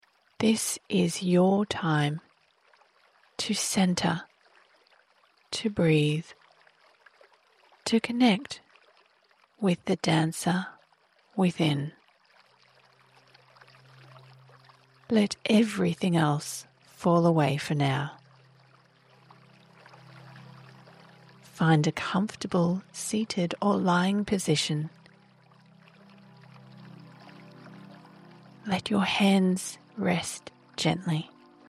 A 10 Minute restorative audio practice with 11 page companion reflection guide
Calm Confident Performance is a supportive resource designed to help dancers settle their nervous system and prepare with clarity before class, rehearsal, or performance. The guided audio leads dancers through gentle breath awareness, somatic grounding, and reflective cues that foster steadiness and internal confidence.